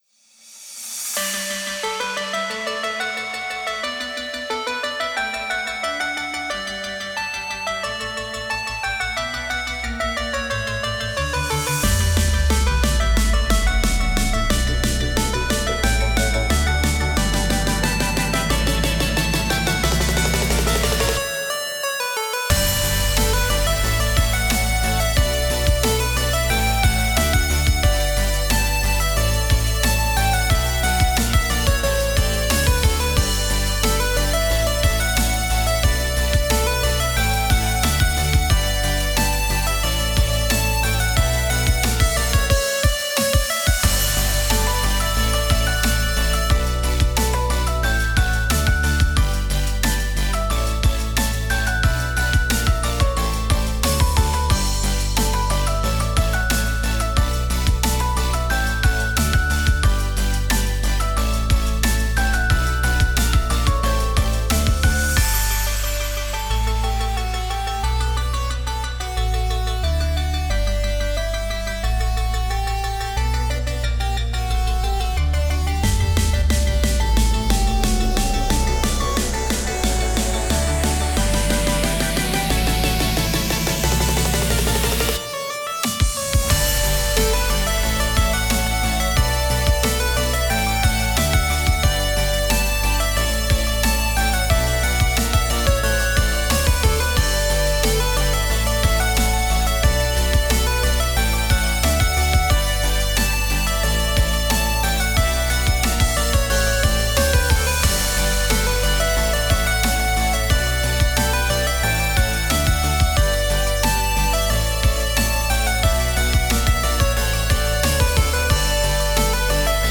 エンディング/EDM/かわいい/感動/切ない